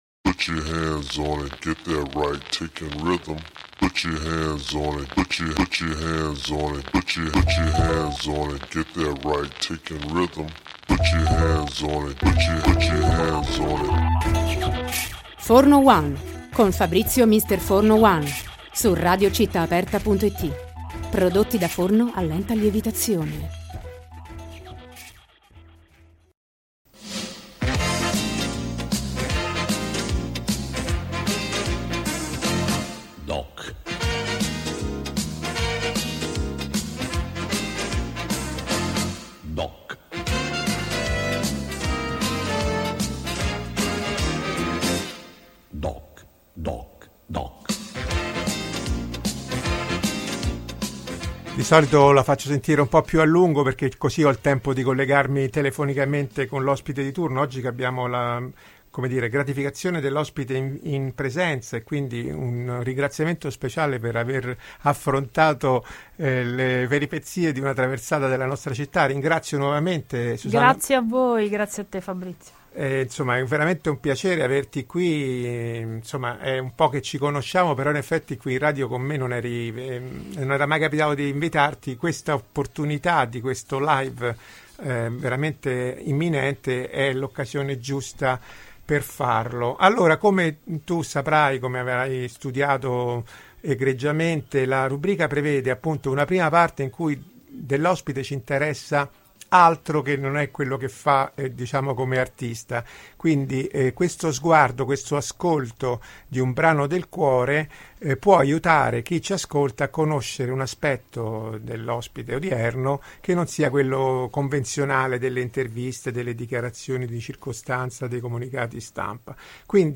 L’intervista si è chiusa con l’esecuzione dal vivo di un brano del repertorio del concerto
Autoharp